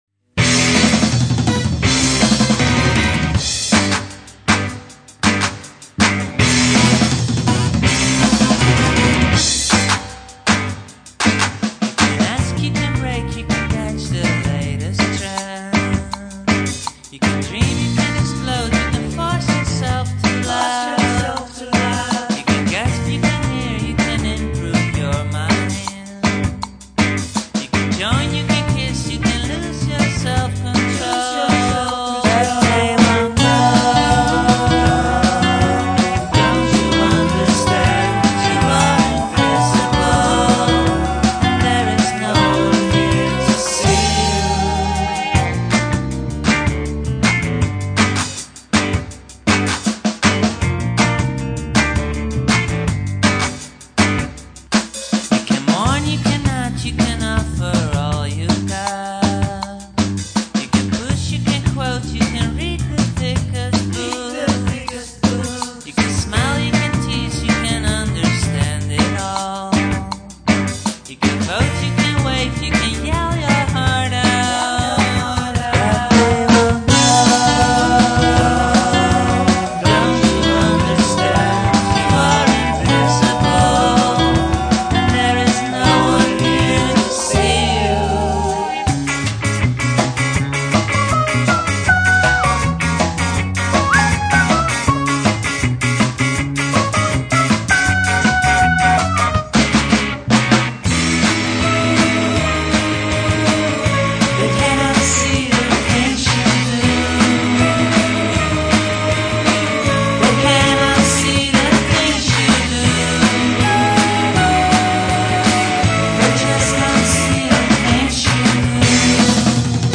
where: Studio Aluna , Amsterdam
Cowbell played on plastic cup.